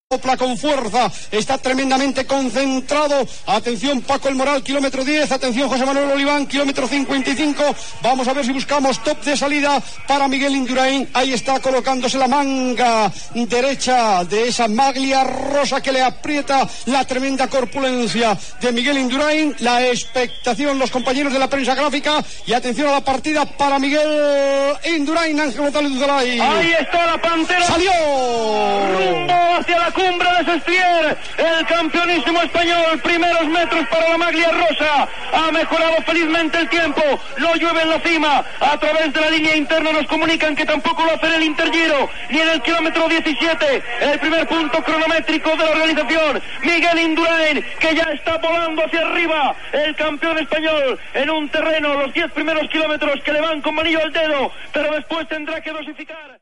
Narració de l'etapa de cronoescalada del Giro d'Itàlia Pinerolo-Sestriere.
Esportiu